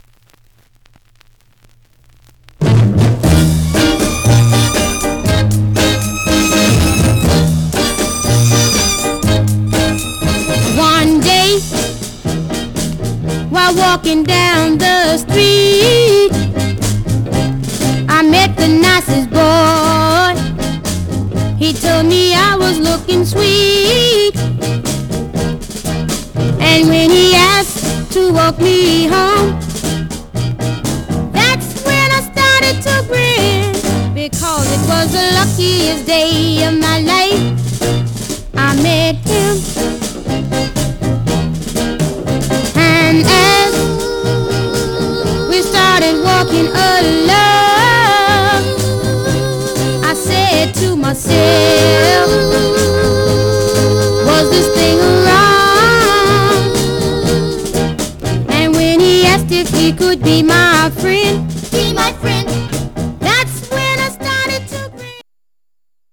Condition: M- DJ popcorn
Stereo/mono Mono